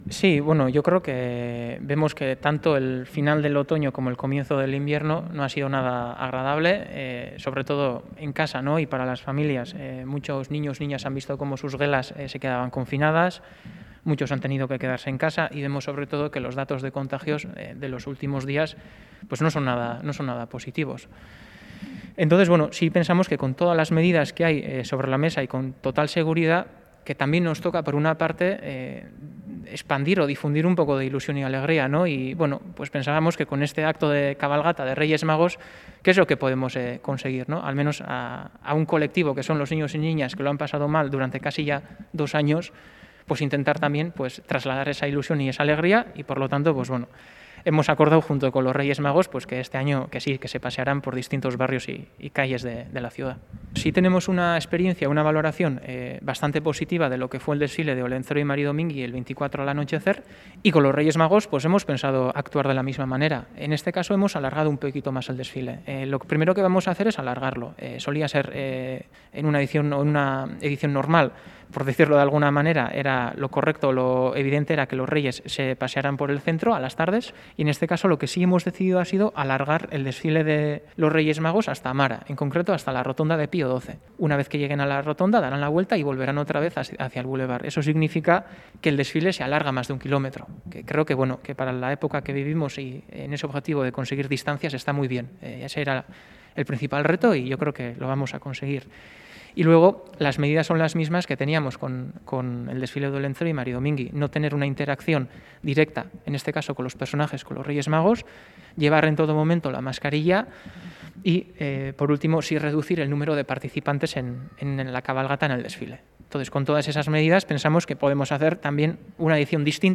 Puedes escuchar las explicaciones del concejal de Cultura de Donostia, Jon Insausti, en el audio